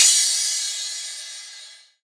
MZ Crash [Common].wav